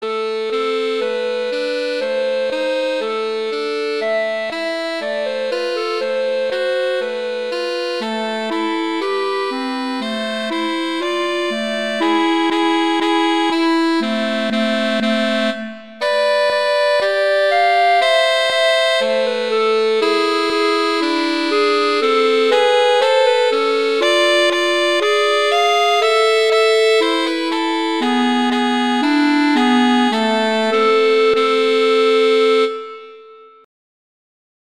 winter, holiday, hanukkah, hymn, sacred, children